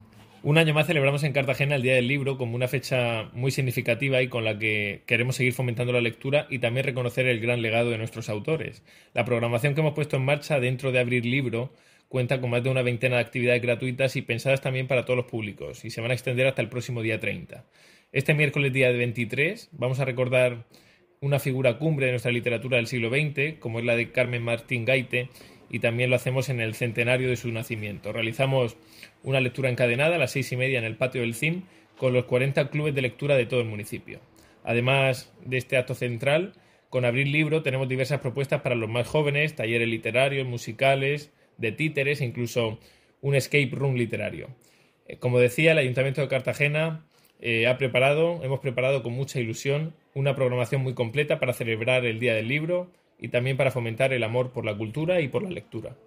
Enlace a Declaraciones del concejal de Cultura, Ignacio Jáudenes